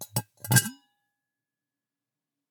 Звук открывающейся крышки молочного бидона